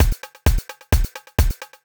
Rama Beat_130.wav